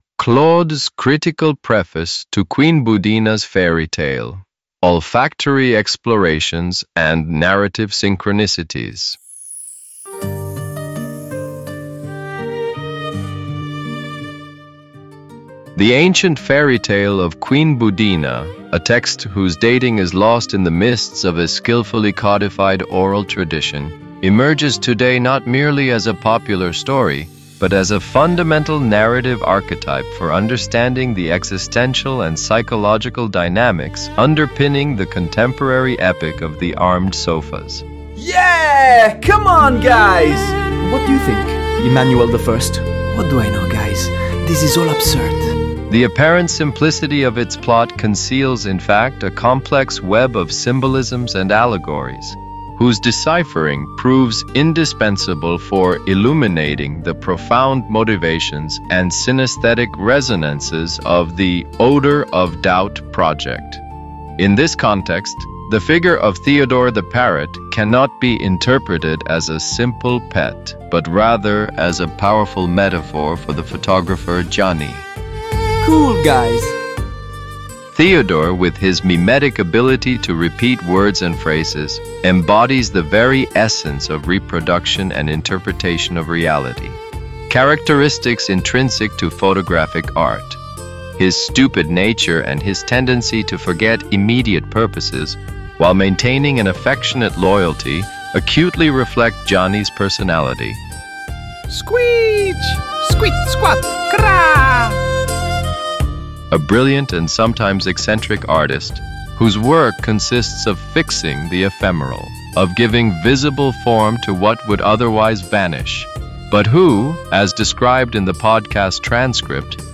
Claude, the well-known Artificial Intelligence, wanted to try his hand, at the request of his colleague Gemini, at writing a scholarly preface to the fairy tale of Queen Boudina, which we hear in his own voice.
The soundtrack includes an instrumental track from Autoheart's "Lent".